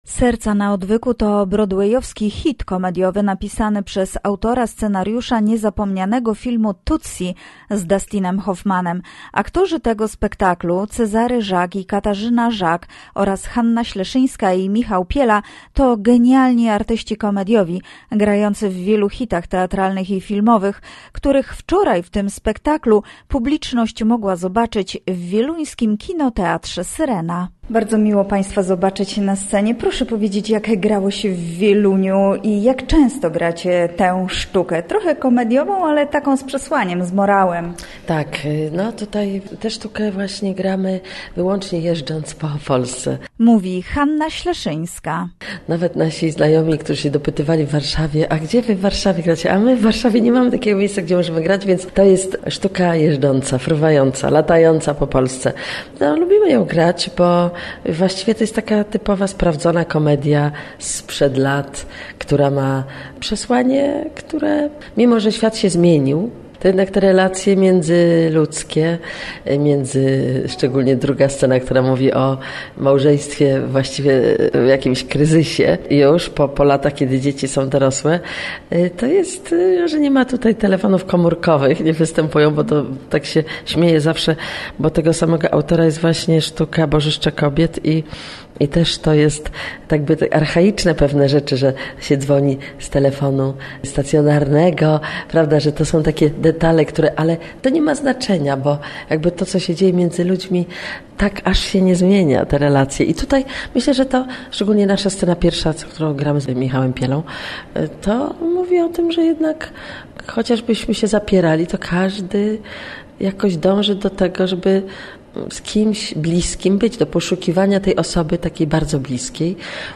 Gościem Radia ZW była Hanna Śleszyńska, aktorka filmowa i teatralna